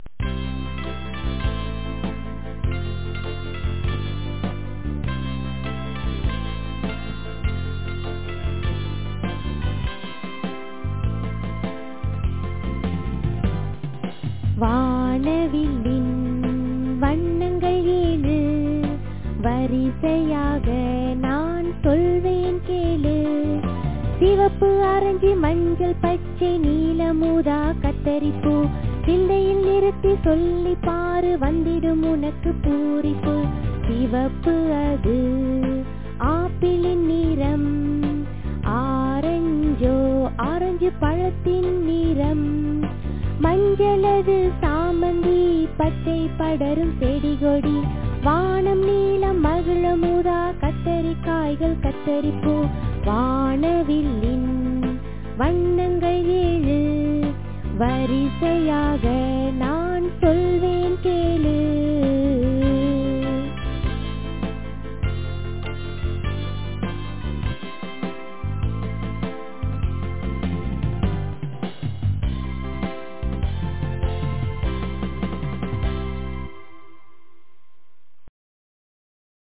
In the final version, the sound recording and the singer's voice will be more professional.